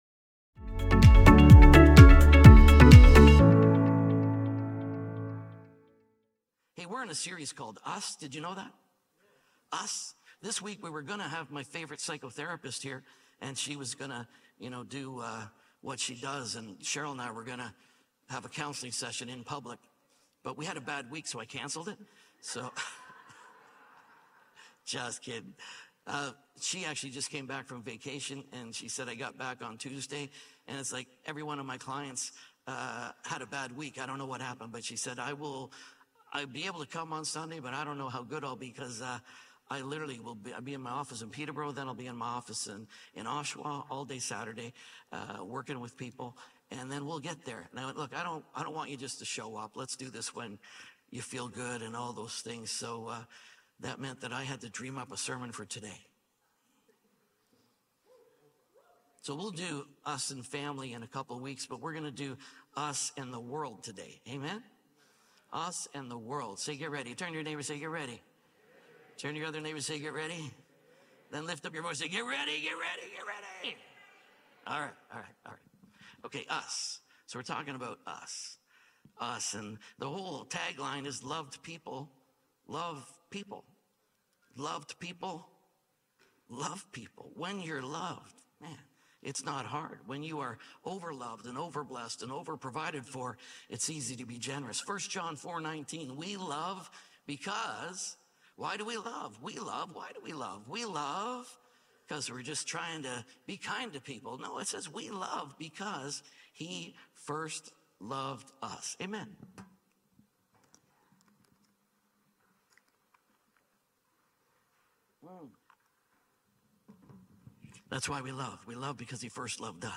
Us and The World | Us Series | SERMON ONLY .mp3